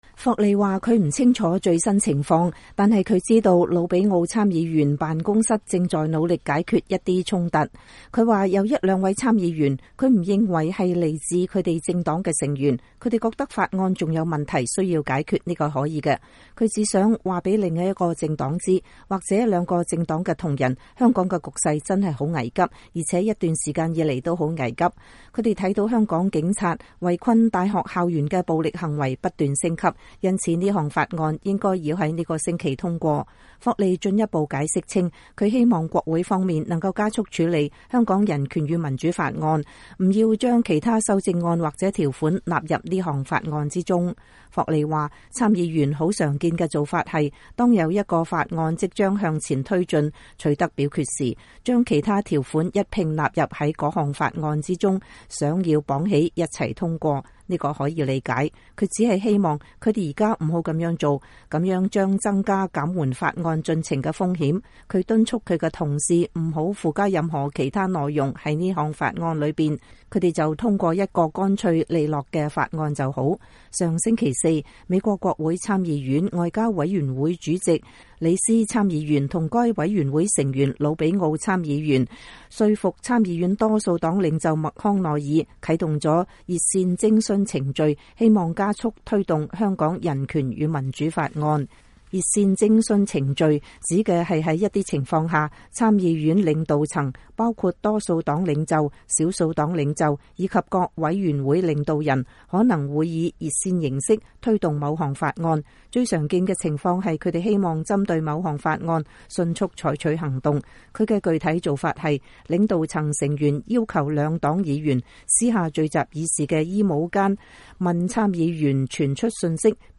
美國聯邦參議員霍利在國會大廈內接受美國之音採訪。(2019年11月18日)